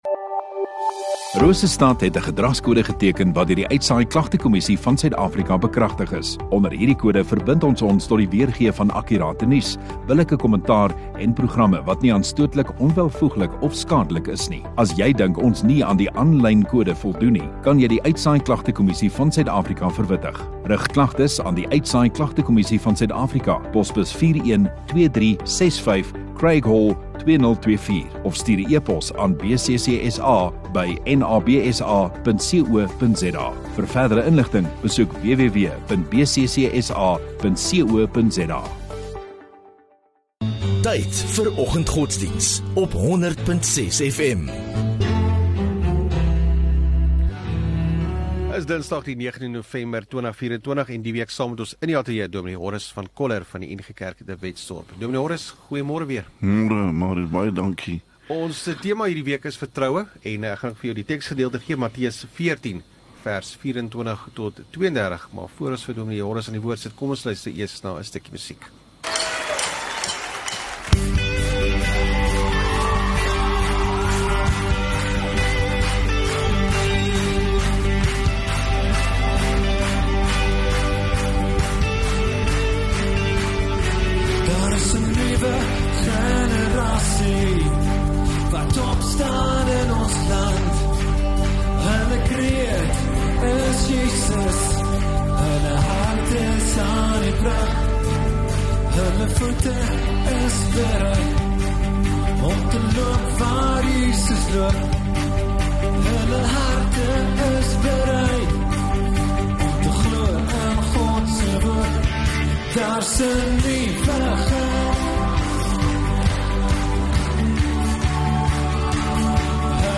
19 Nov Dinsdag Oggenddiens